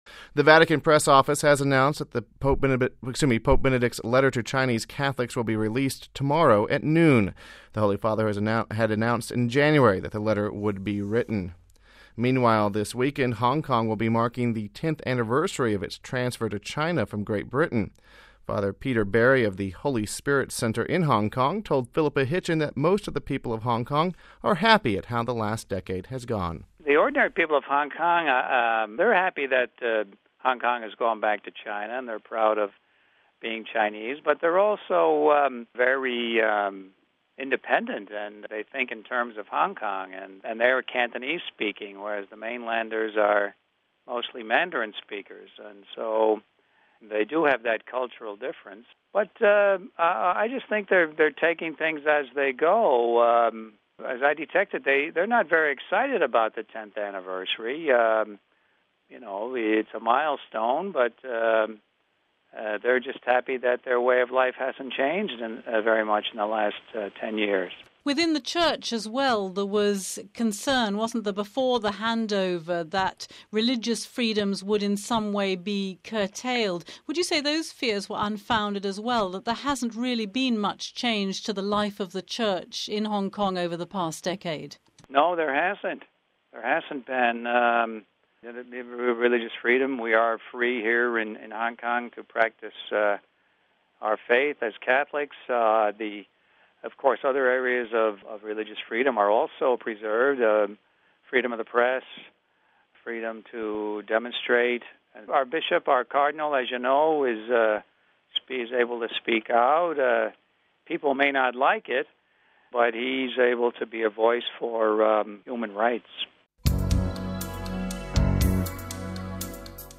(29 Jun 07 - RV) Pope Benedict XVI is to release his letter to Chinese Catholics on Saturday. Meanwhile, Hong Kong prepares to celebrate 10 years of Chinese rule. We have this report...